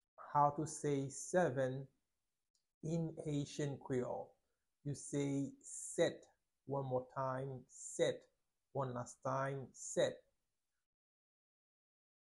Pronunciation:
10.How-to-say-seven-in-Haitian-Creole-–-Set-pronunciation-.mp3